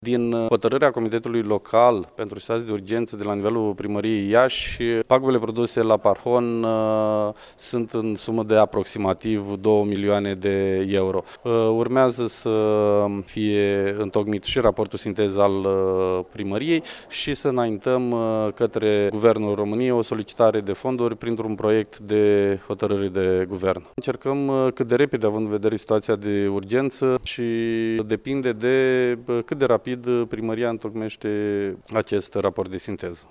Prefectul de Iaşi, Marian Şerbescu, a precizat că până în prezent există o centralizare a pagubelor şi a necesarului financiar doar la nivelul Institutului de Boli Cardiovasculare: